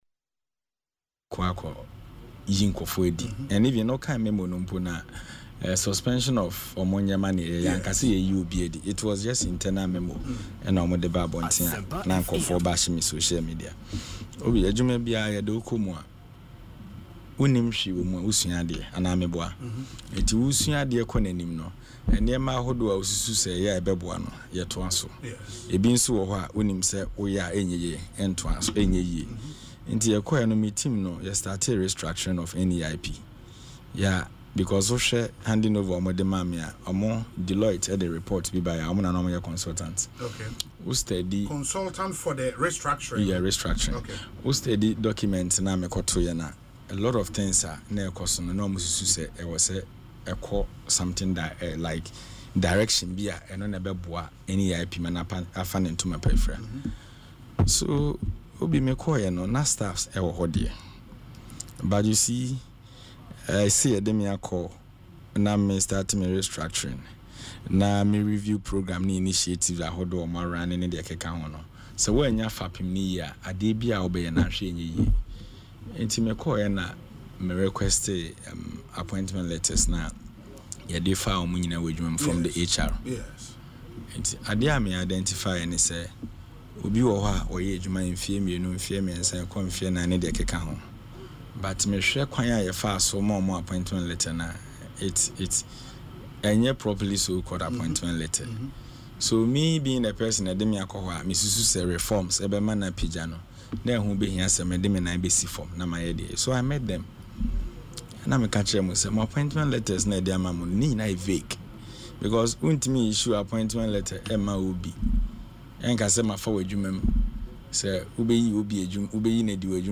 Speaking in an interview on Asempa FM’s Ekosii Sen, Mr. Adjei clarified that the affected employees have not been issued termination letters.